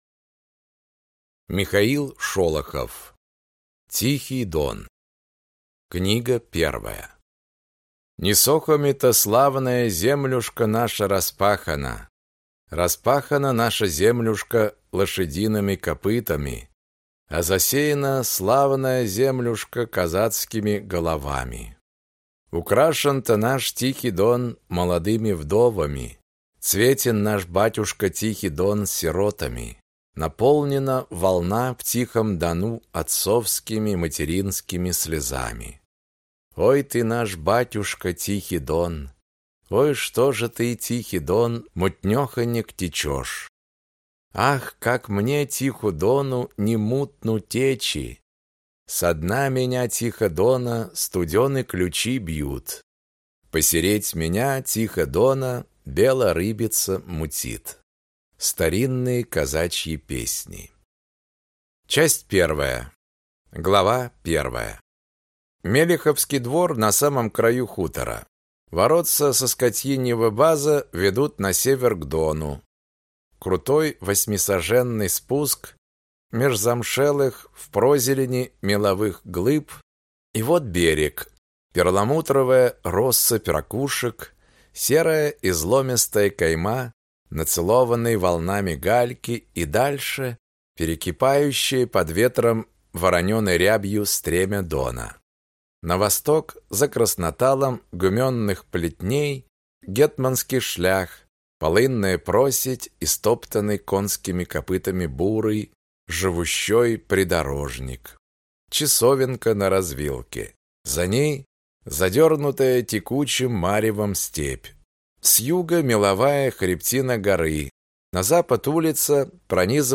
Аудиокнига Тихий Дон | Библиотека аудиокниг